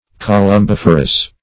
Search Result for " columbiferous" : The Collaborative International Dictionary of English v.0.48: Columbiferous \Col"um*bif"er*ous\, a. [Columbium + -ferous.] Producing or containing columbium.
columbiferous.mp3